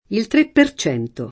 tre [tr%+] num.